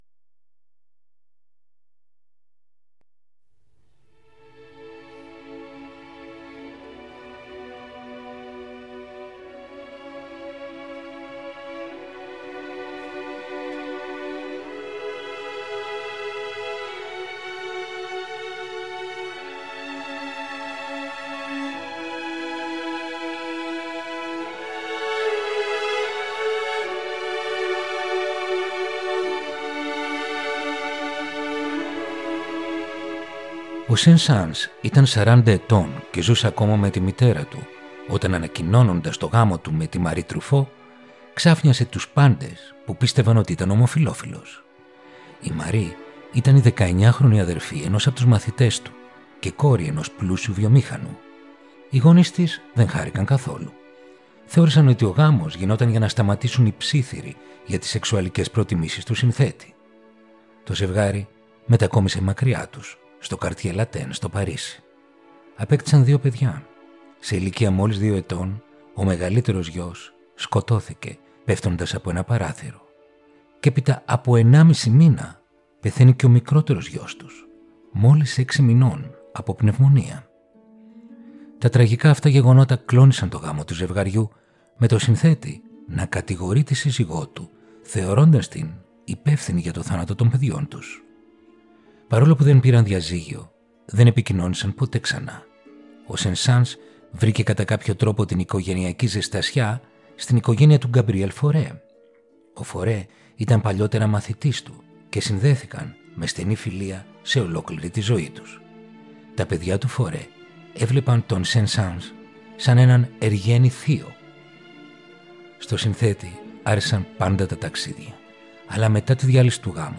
Ρομαντικά κοντσέρτα για πιάνο – Επεισόδιο 13ο